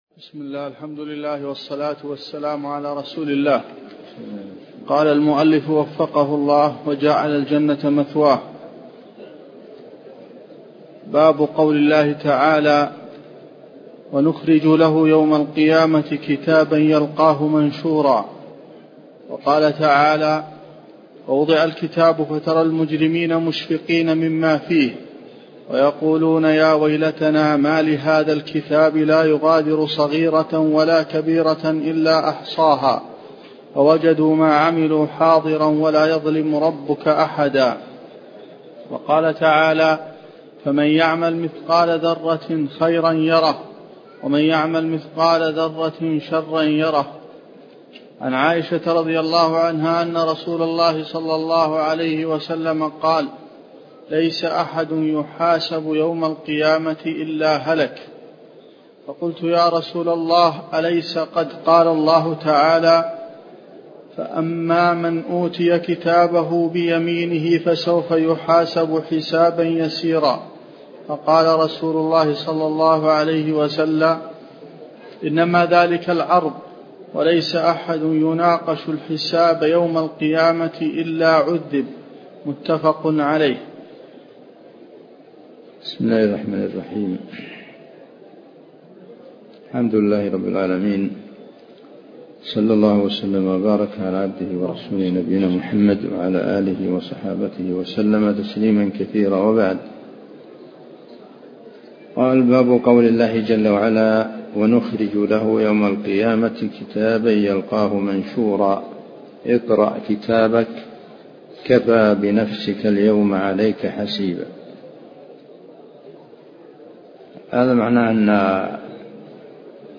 تفاصيل المادة عنوان المادة الدرس (16) شرح المنهج الصحيح تاريخ التحميل الأحد 15 يناير 2023 مـ حجم المادة 32.32 ميجا بايت عدد الزيارات 317 زيارة عدد مرات الحفظ 110 مرة إستماع المادة حفظ المادة اضف تعليقك أرسل لصديق